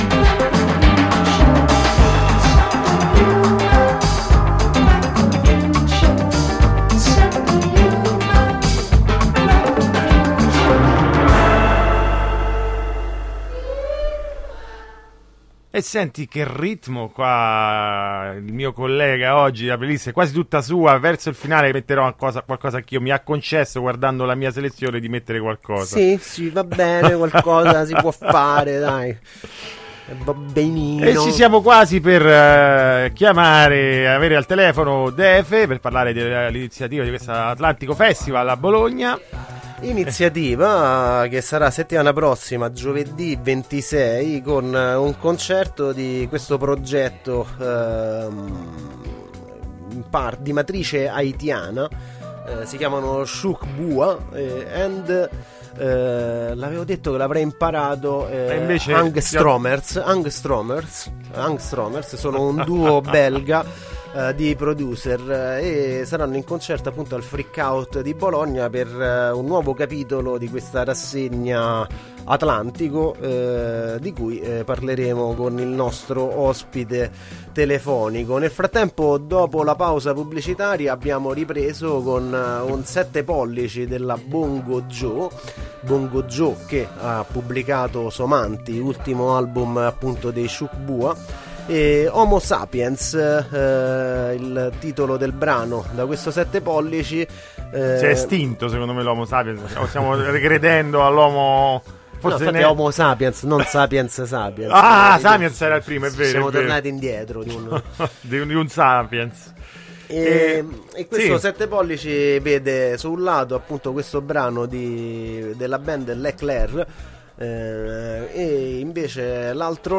Groovy Times intervista Atlantico Festival | Radio Città Aperta